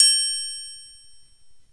triangl2.mp3